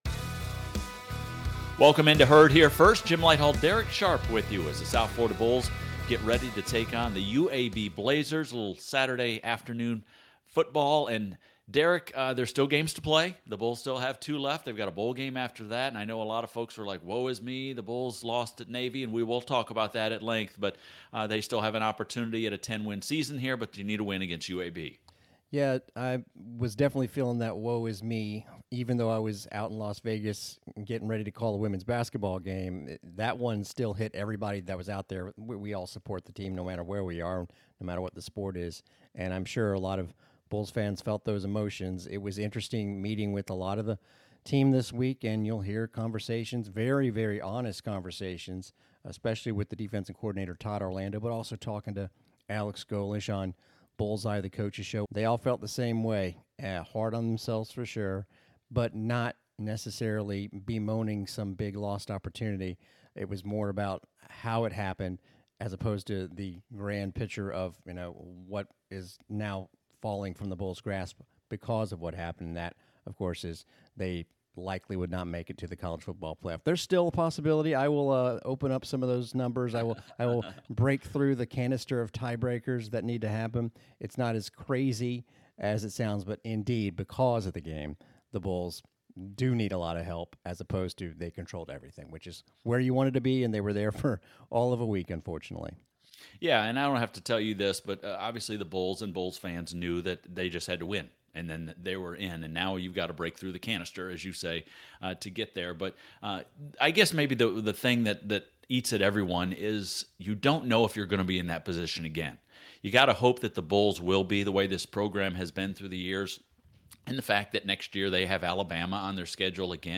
Herd Here First: Hour 1 Radio Pregame Show USF at UAB